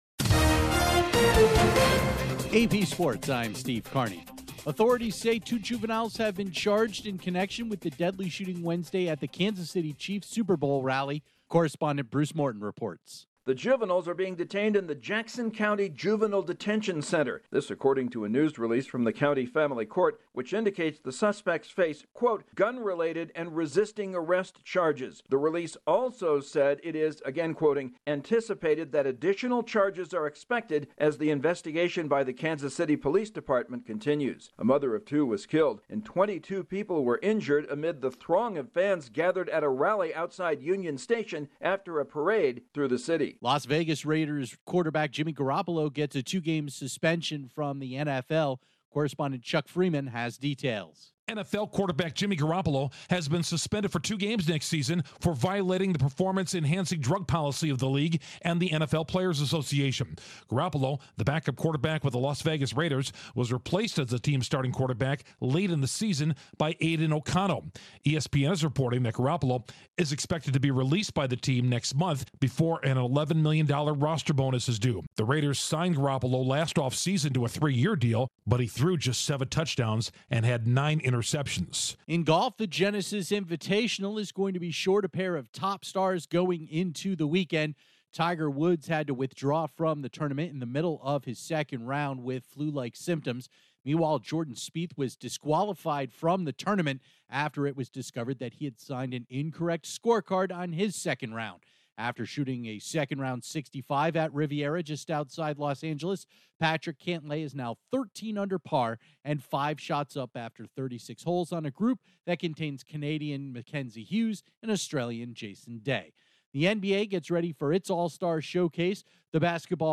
Sports News from the Associated Press